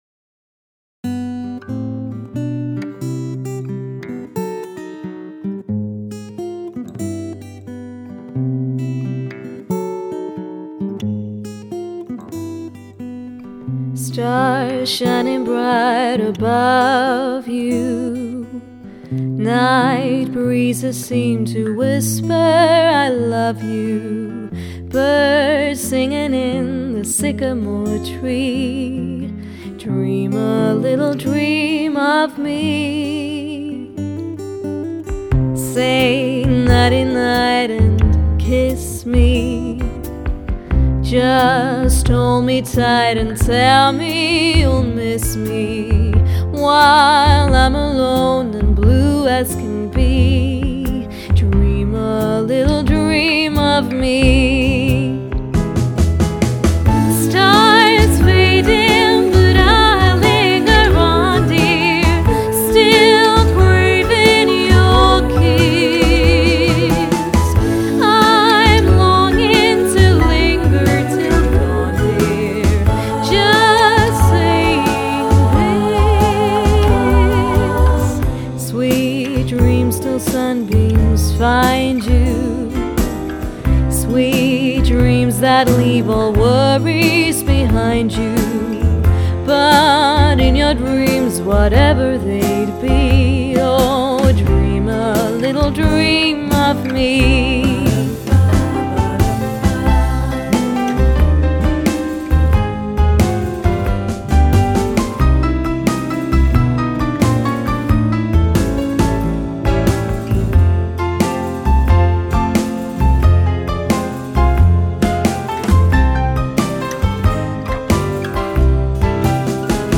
• Jazz
• Unplugged
• Sänger/in